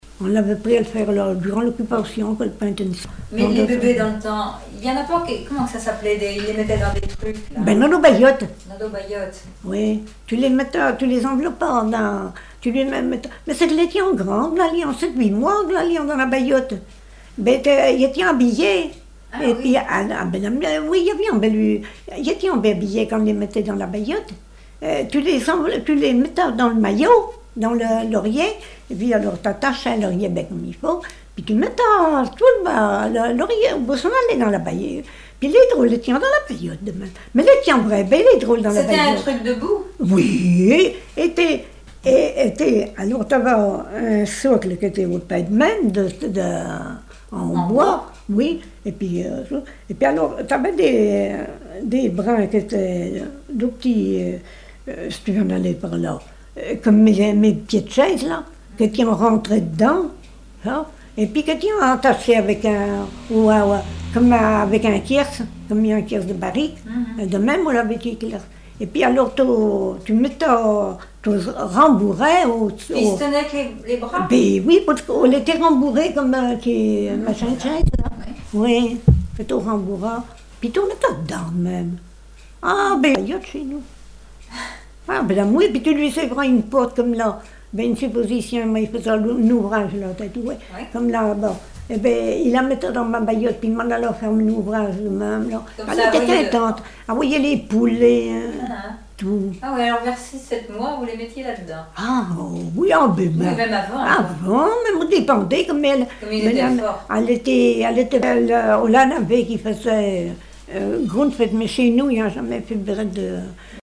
Langue Patois local
Catégorie Témoignage